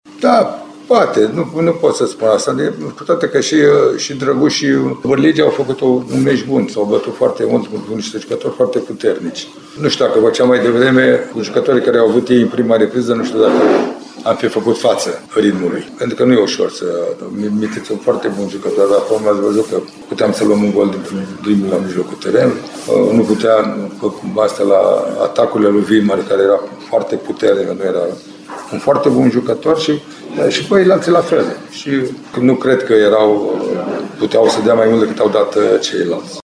Selecționerul a fost întrebat și dacă nu regretă că a făcut schimbările târziu:
3-Lucescu-daca-nu-regreta-schimbarile-tarzii.mp3